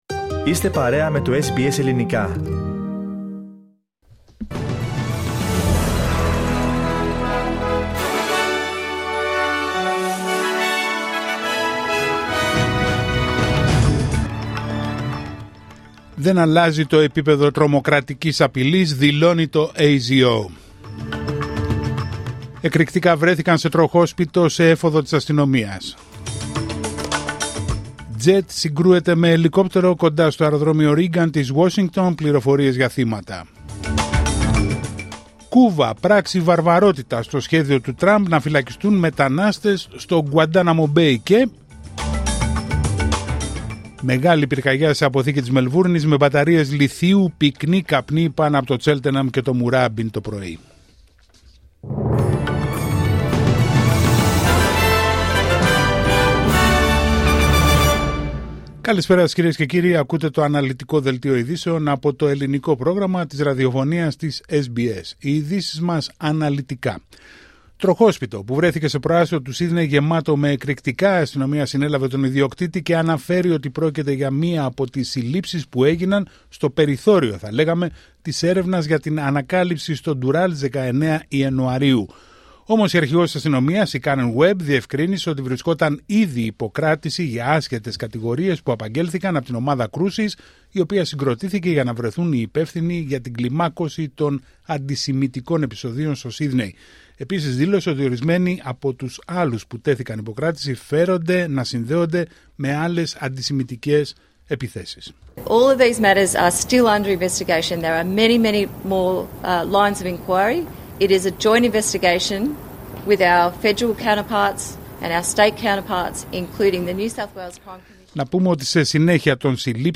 Δελτίο ειδήσεων Πέμπτη 30 Ιανουαρίου 2025